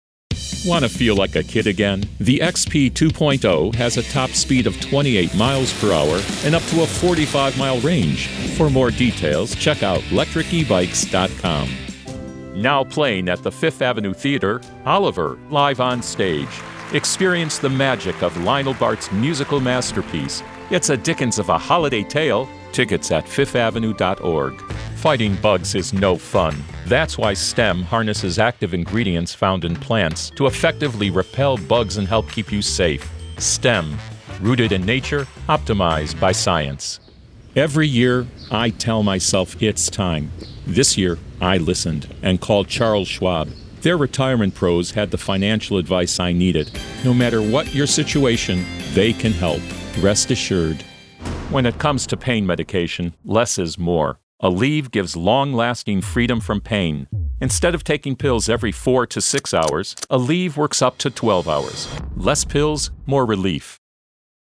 English (American)
Commercial, Deep, Senior, Mature, Friendly
Commercial